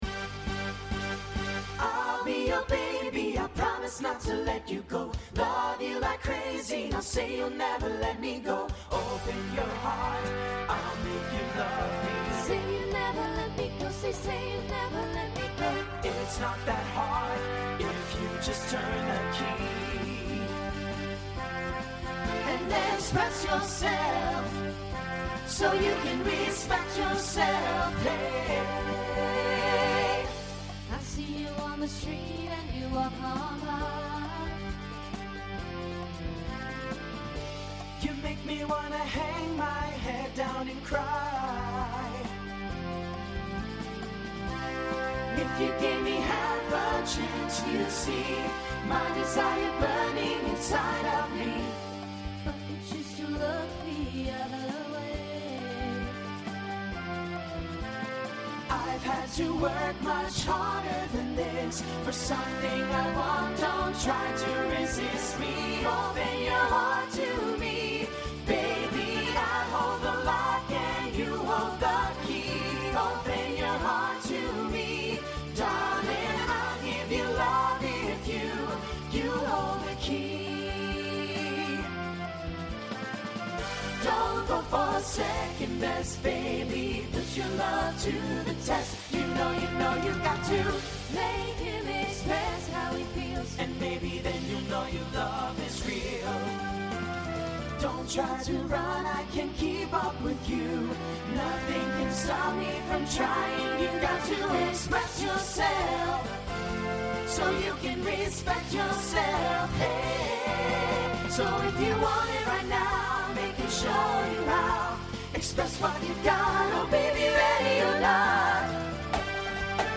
With drumline parts.
Voicing SATB Instrumental combo Genre Pop/Dance